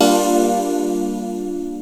SYNTH GENERAL-3 0004.wav